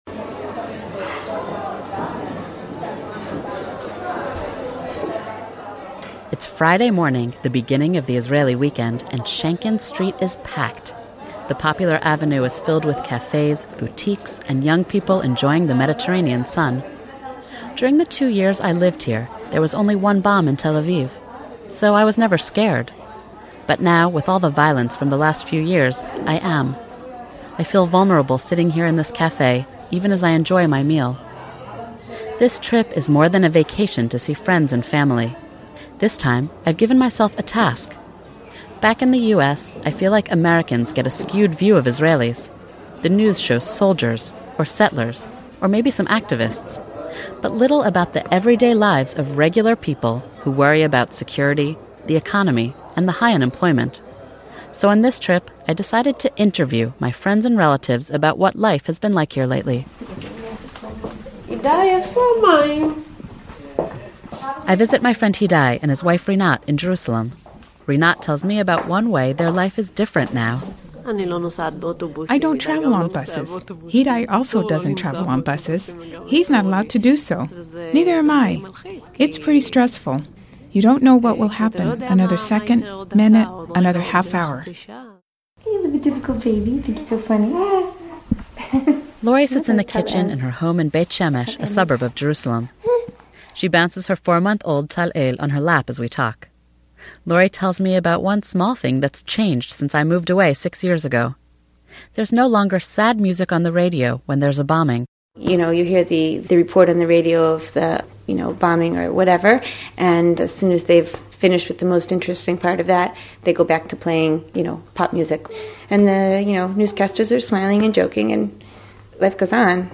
In 2004, following years of intense bombing there, I visited Israel. I’d lived there for years, but it was my first time back since the start of the second intifada, so I interviewed friends and relatives to learn what had changed.